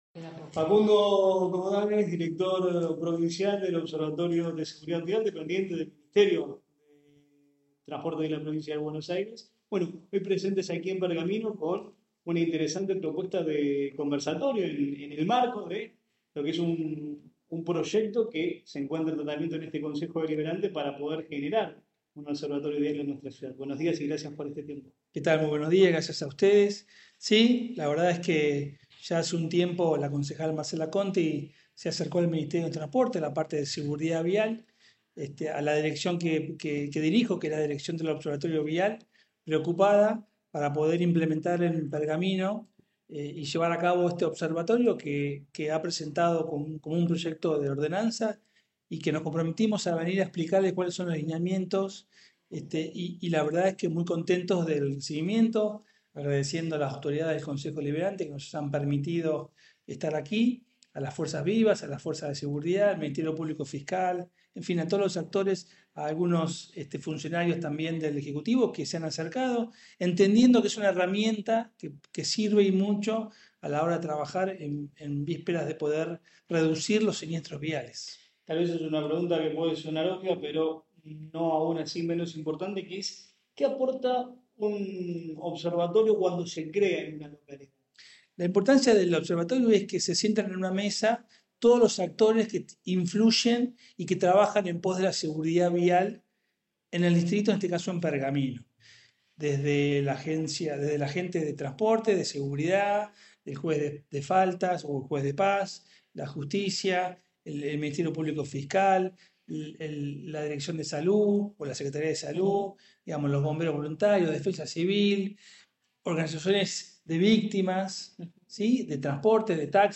Tras finalizar la jornada, dialogó con la prensa junto a la concejal Marcela Conti, autora del proyecto para un observatorio local: